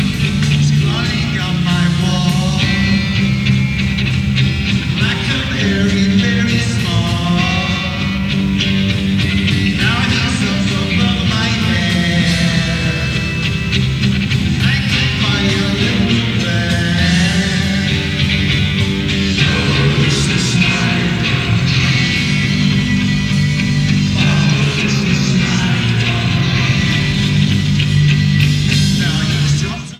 Format/Rating/Source: CD - C- - Audience
Comments: Fair audience recording
Sound Samples (Compression Added):